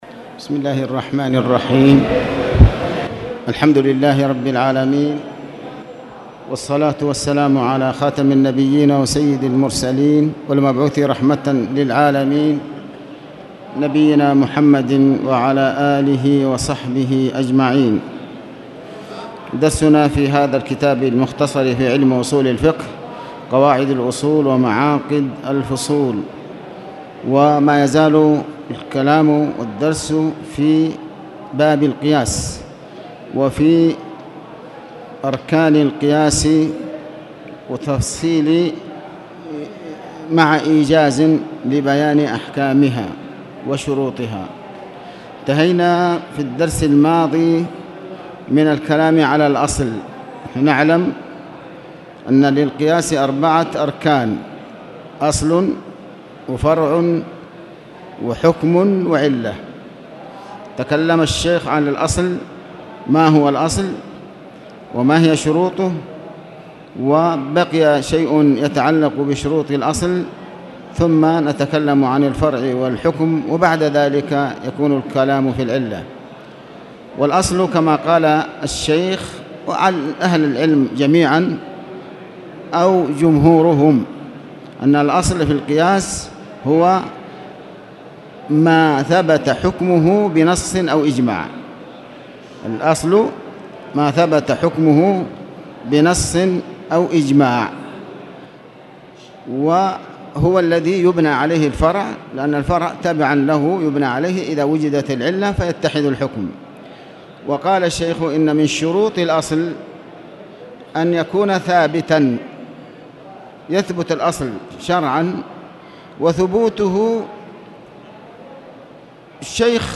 تاريخ النشر ٢٥ محرم ١٤٣٨ هـ المكان: المسجد الحرام الشيخ: علي بن عباس الحكمي علي بن عباس الحكمي باب القياس